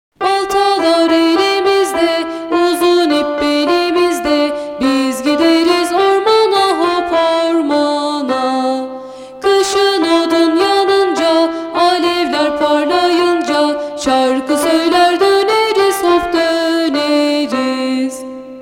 Çocuk Şarkıları